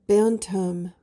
PRONUNCIATION: (BAN-tuhm) MEANING: noun:1.